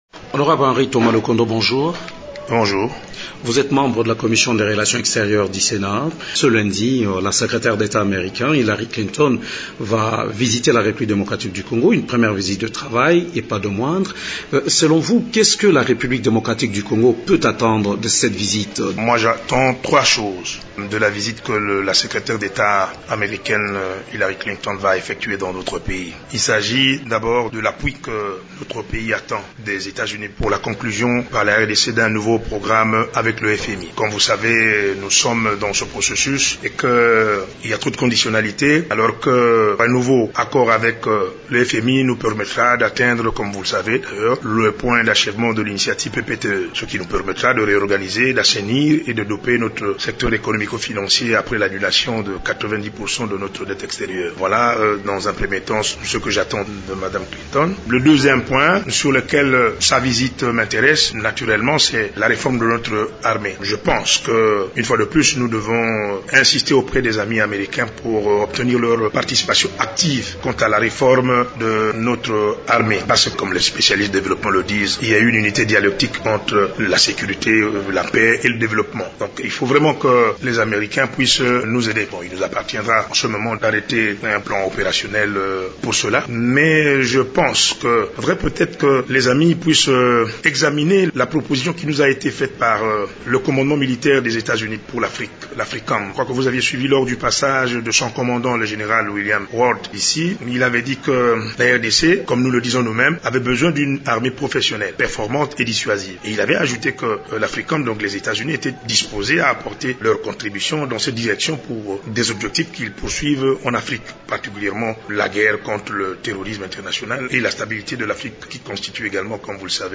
Nous en parlons avec notre invité du jour, Henri Thomas Lokondo.
Ce dernier est un ancien vice ministre des affaires étrangères de la RDC et actuellement membre de la commission des relations extérieures du sénat.